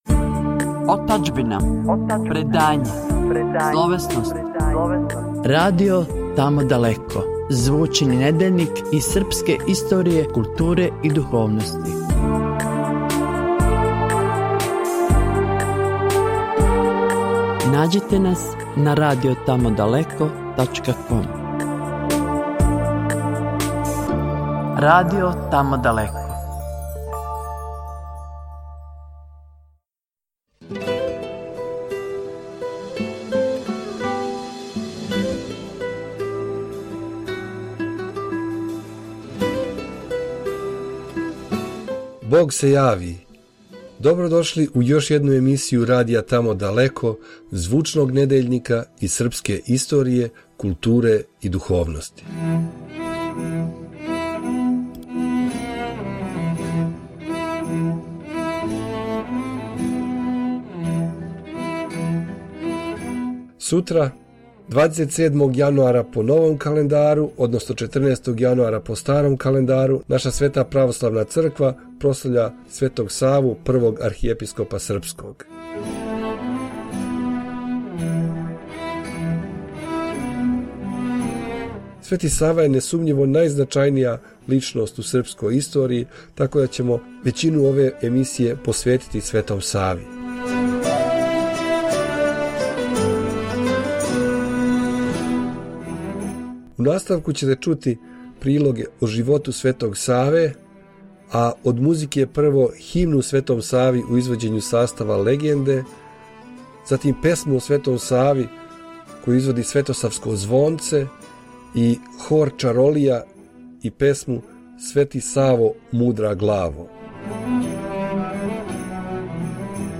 У овој епизоди: – Свети Сава (04:44) – Значај Светог Саве (09:37) – Светосавље (14:53) – Богородица Млекопитатељница (20:49) – Свети Саво – народна песма (29:37) – Моје село (36:54) … и пуно, пуно лепе музике…